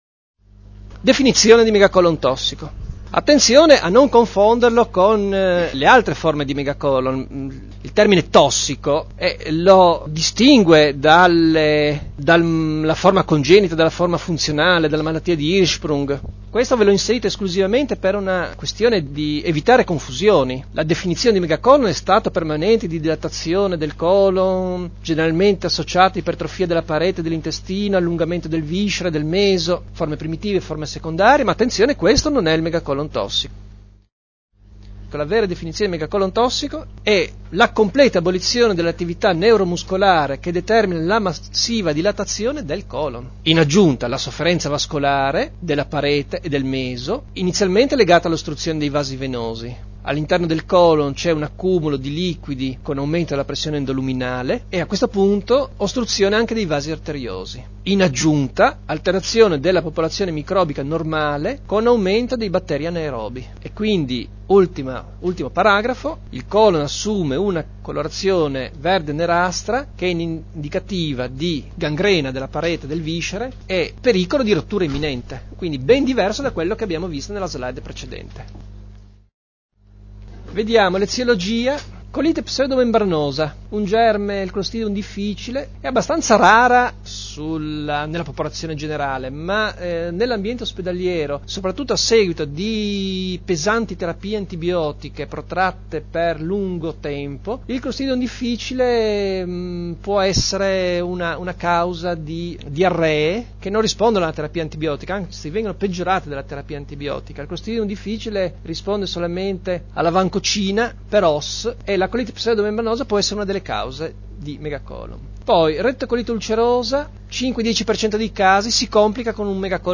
registrazione dell'audio della lezione dal vivo
lezione_megacolon.mp3